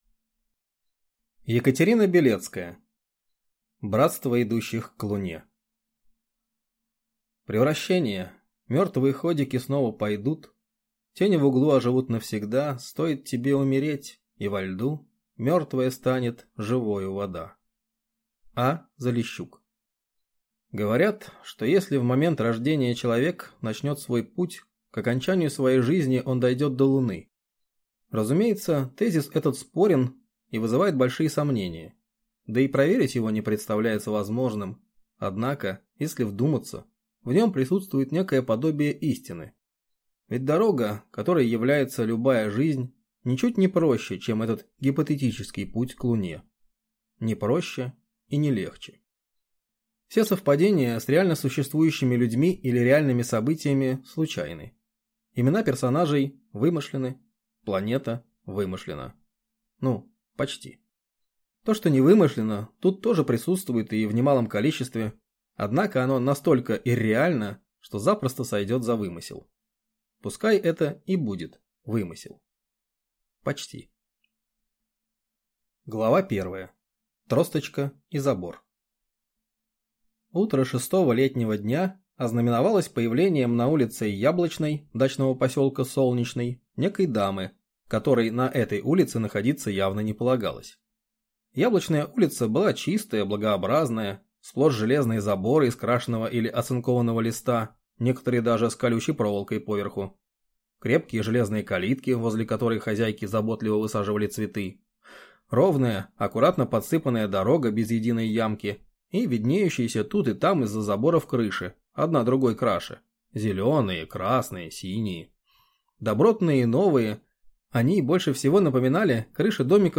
Аудиокнига Братство идущих к Луне | Библиотека аудиокниг